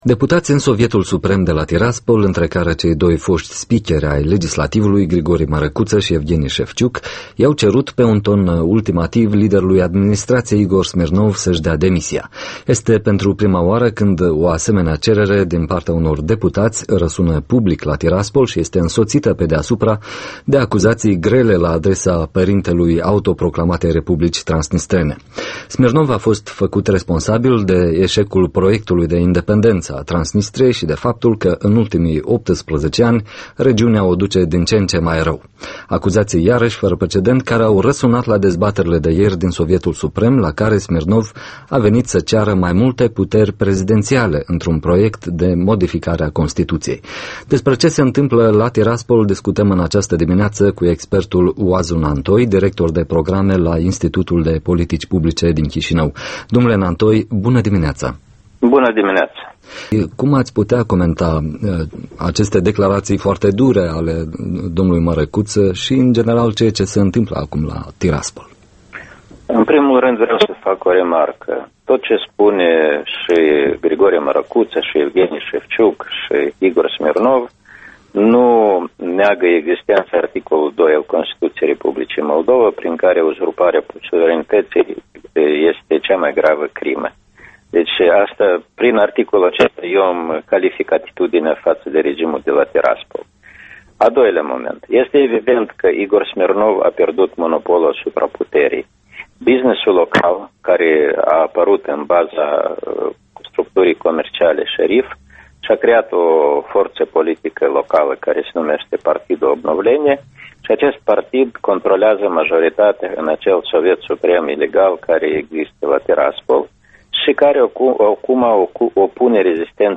Interviul matinal cu expertul Oazu Nantoi